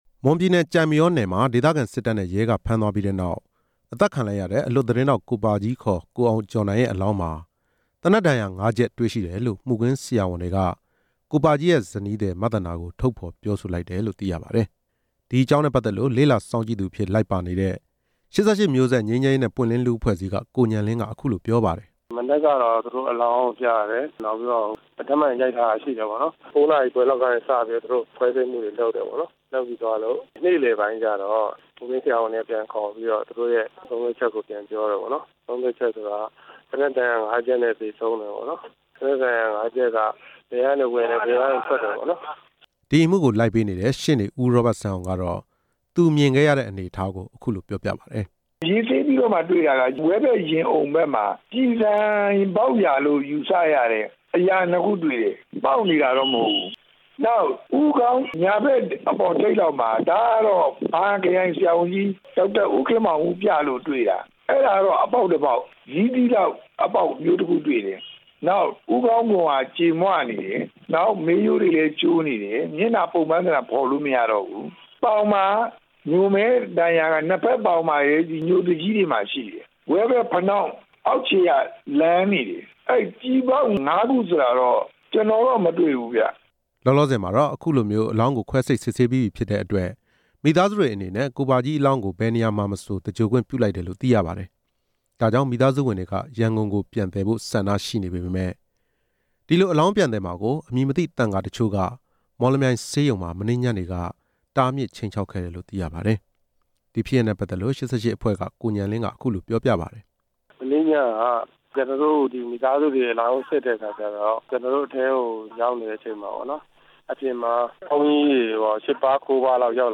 တင်ပြချက်